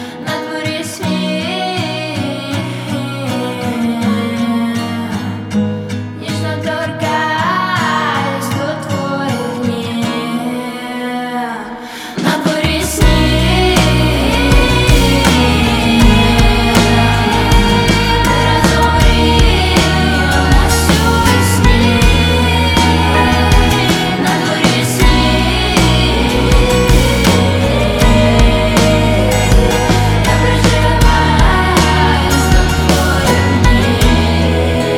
Жанр: Иностранный рок / Рок / Инди / Украинские